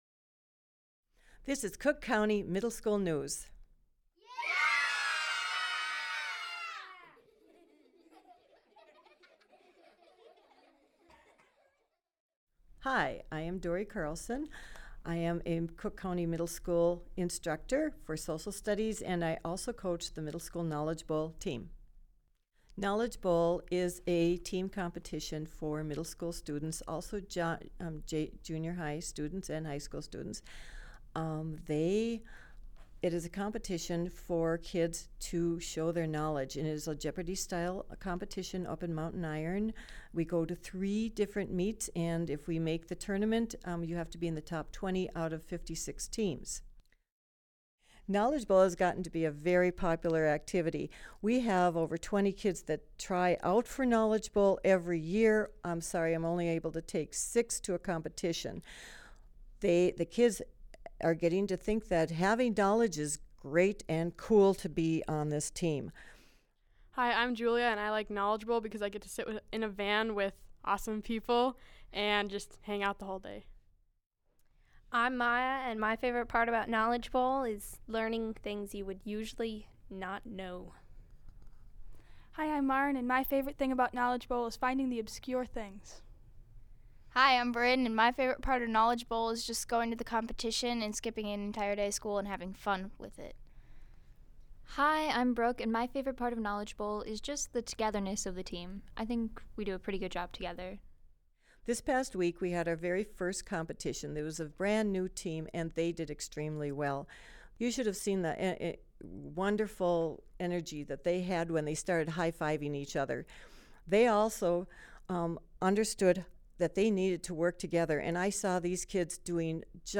School News